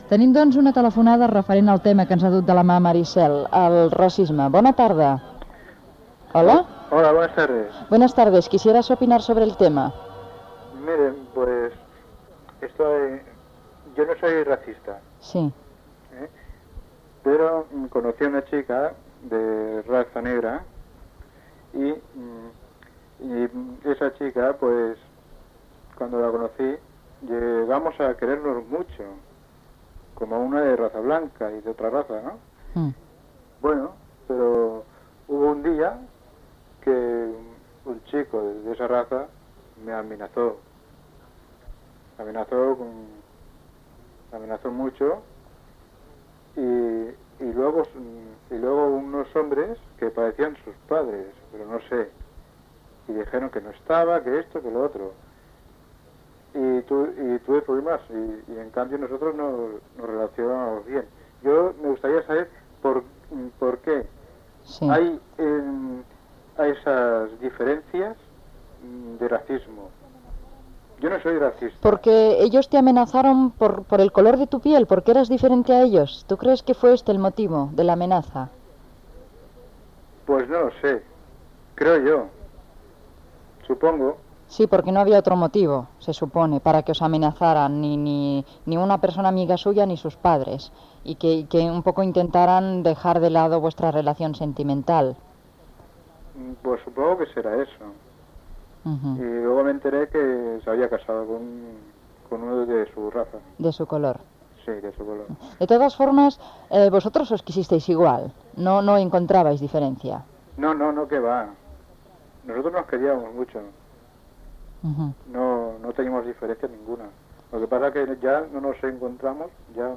Trucada d'un oïdor sobre la seva relació amb una persona d'una altra raça, tema musical, publicitat, indicatiu
Entreteniment